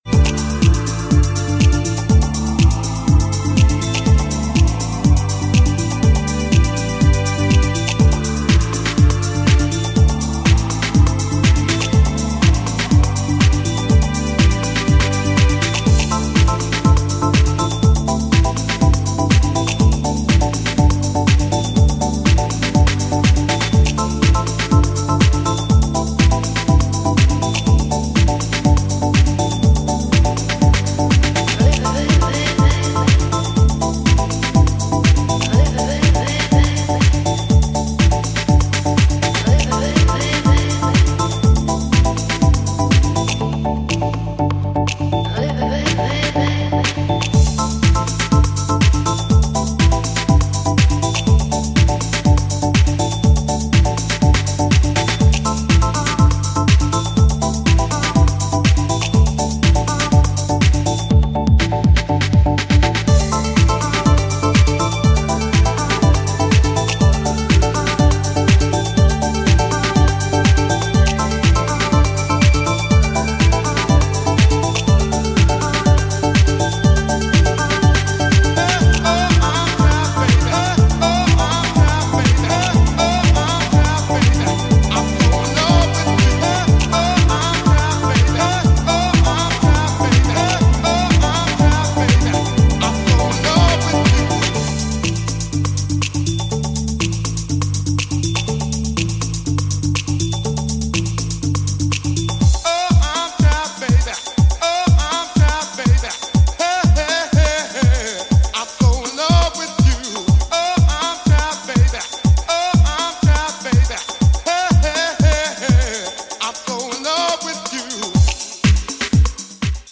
抑制を意識し最もバランスが取れた